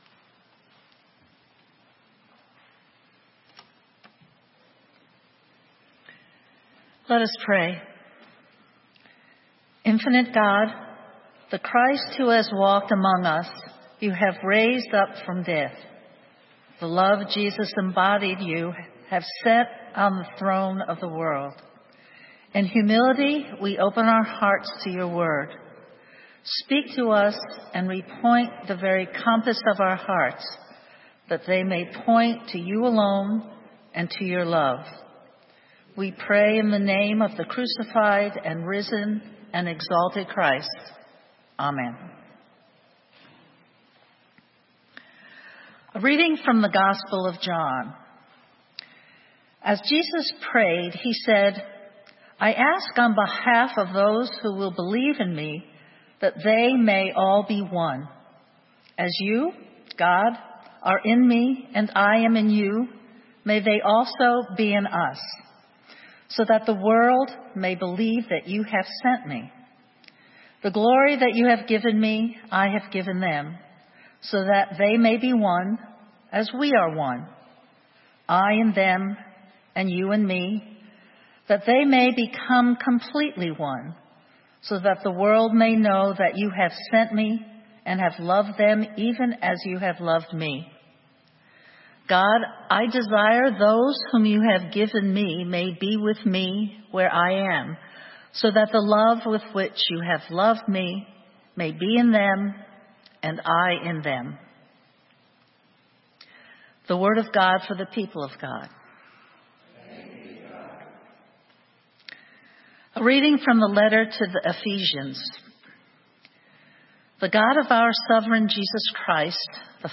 Sermon:Awe and wonder - St. Matthew's UMC